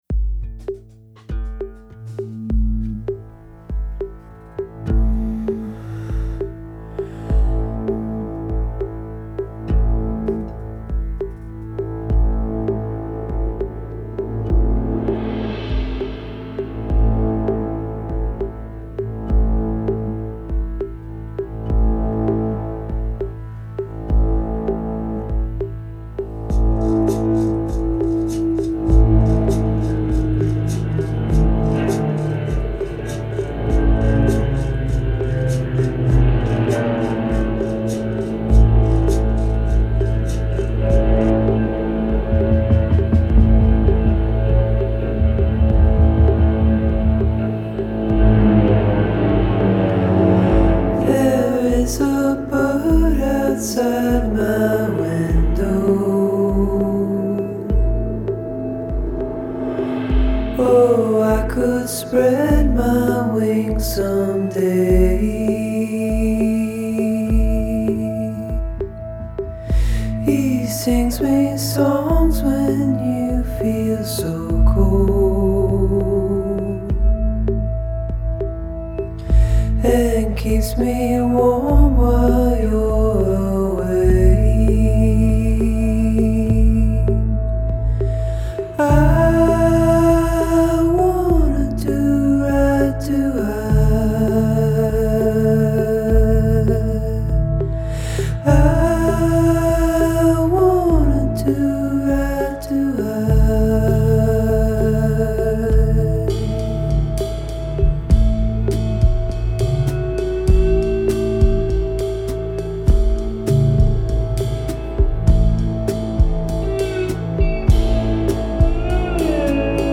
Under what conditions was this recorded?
recorded in Nashville, Chattanooga, and Los Angeles;